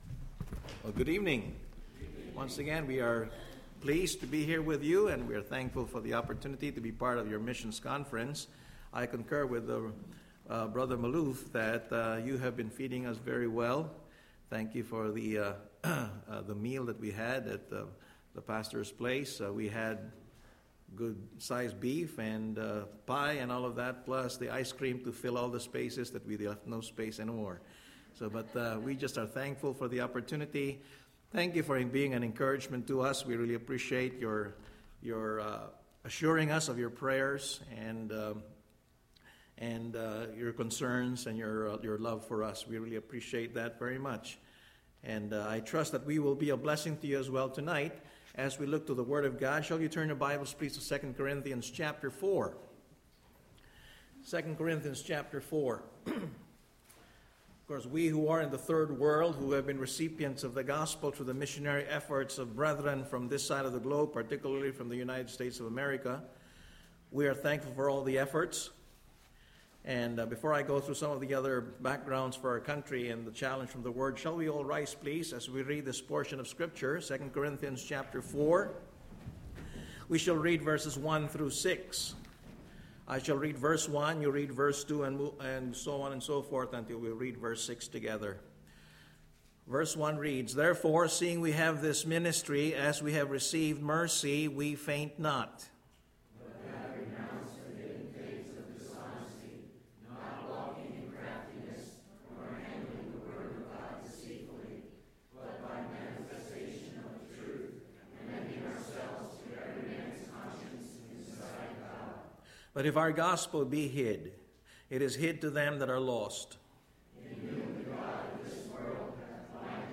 Sunday, September 25, 2011 – Evening Message